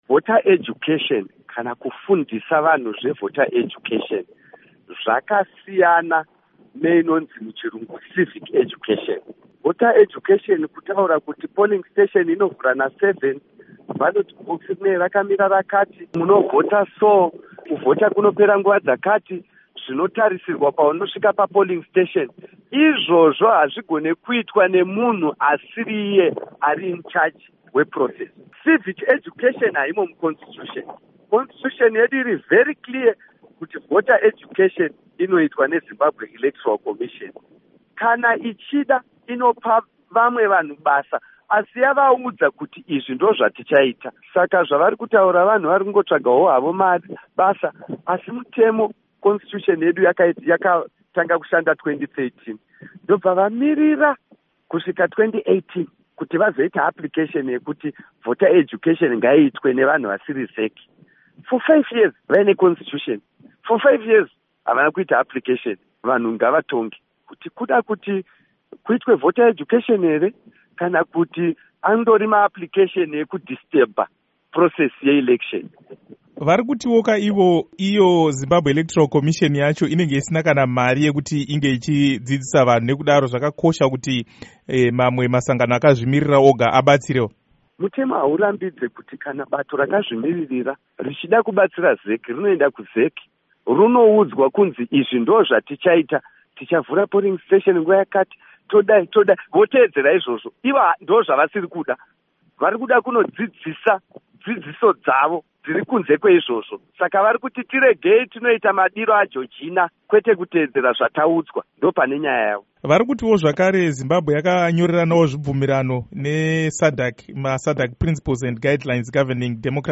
Hururo naVaZiyambi Ziyambi